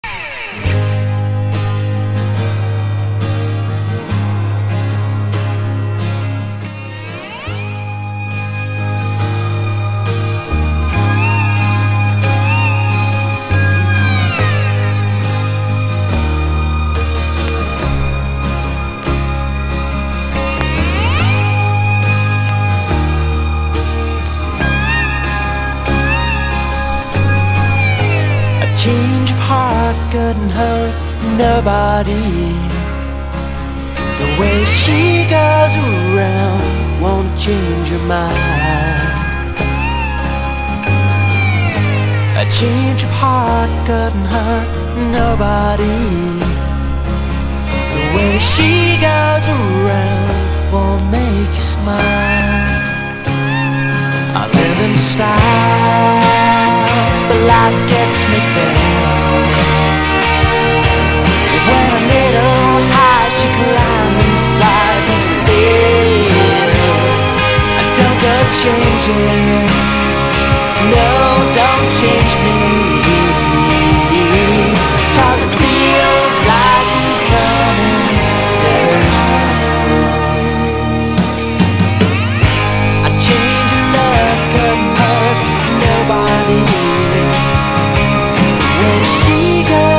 1. 90'S ROCK >
NEO ACOUSTIC / GUITAR POP (90-20’s)